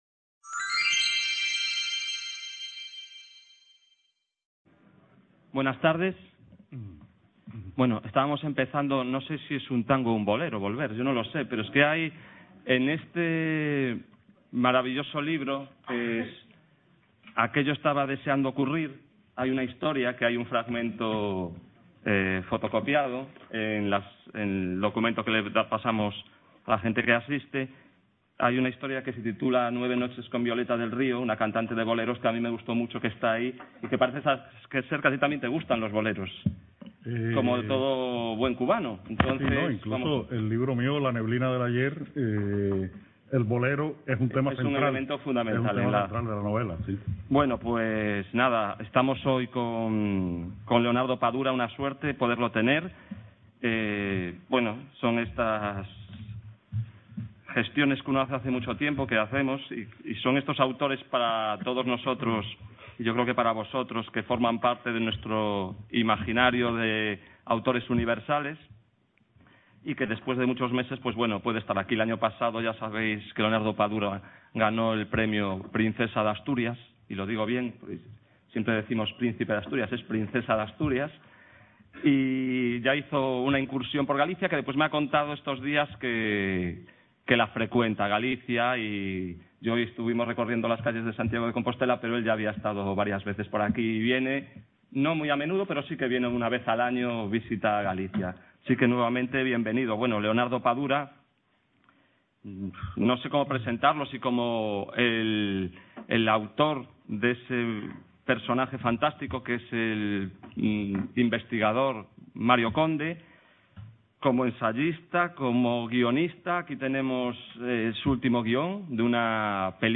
A CREACION LITERARIA E OS SEUS AUTORES. XI ENCONTROS CON ESCRITORES, con Leonardo Padura. Description Encuentro y tertulia con el escritor cubano Leonardo Padura CA La Coruña (A Coruña) - A CREACION LITERARIA E OS SEUS AUTORES.